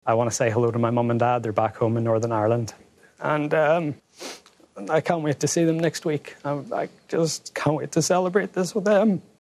In the ceremony to present him with his Green Jacket, he reflected on what the win will mean to his family……..